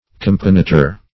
Companator \Com"pa*na`tor\, n.